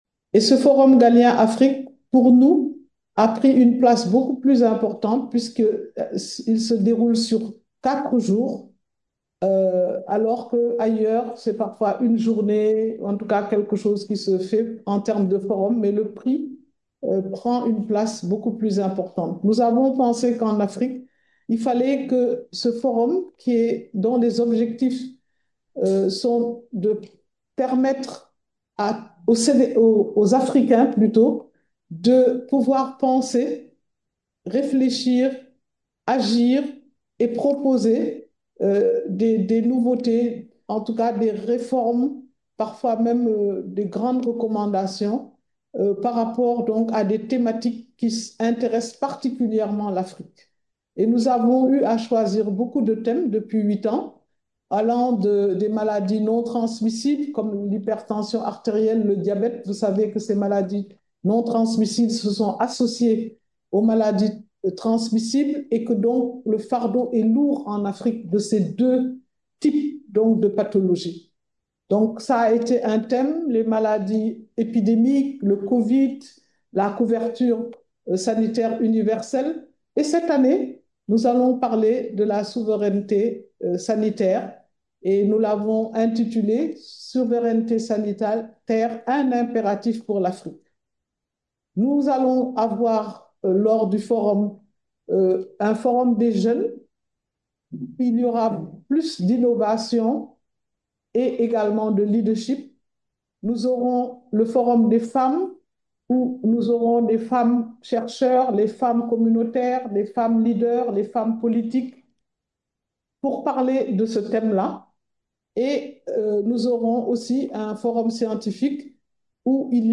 Comme l’a mis en évidence, madame le prefesseur Awa  Marie Coll Seck.
AWA-MARIE-COLL-SECK.mp3